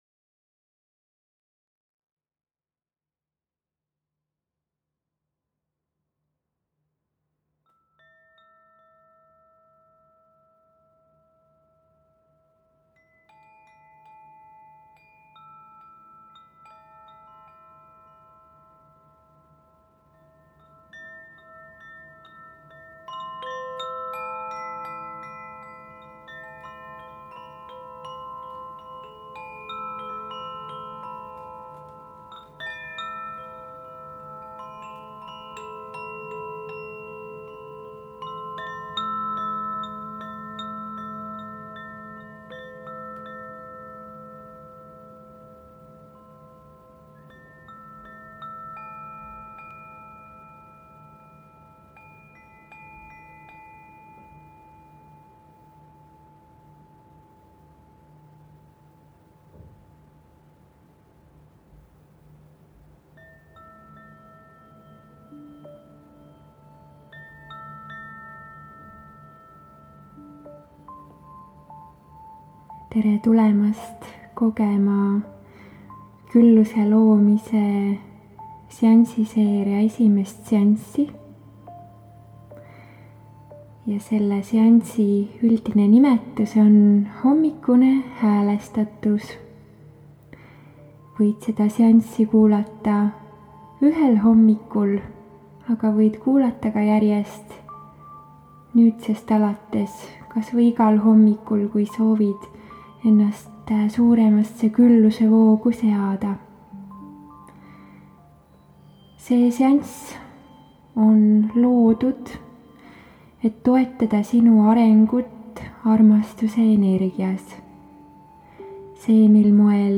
Võid seansi ajal minuga koos taotlusi lausuda või jääda ka lihtsalt rahusse, lõdvestuse ja lubada energiatel läbi enda voolata. Iga kord seda meditatsiooni kuulates saavad puhastatud Su meel, teadvus, keha ja süda.